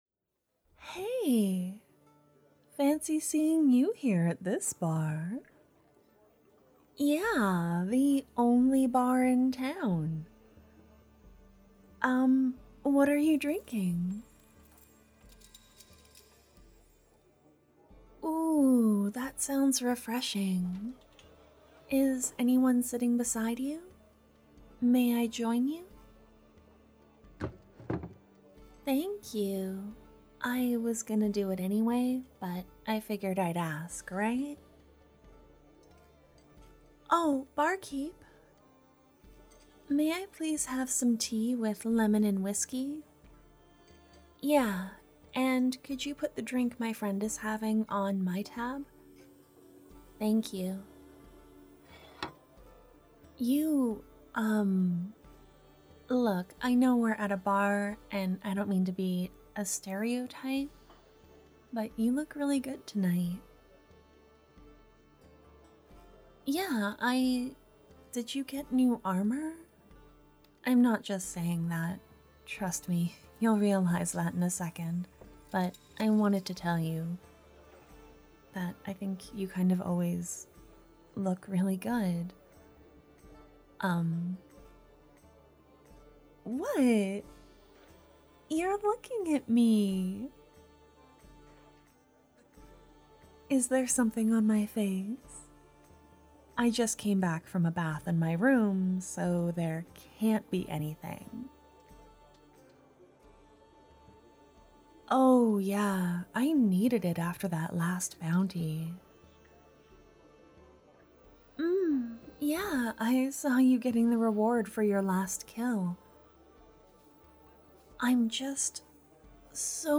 I am (thankfully) working with some new hardware and I believe the baseline volume for the audios will be a bit louder from now on.
Edits: Cleaned up the audio some more, I noticed a quiet 'hiss' in the background when I listened with different headphones.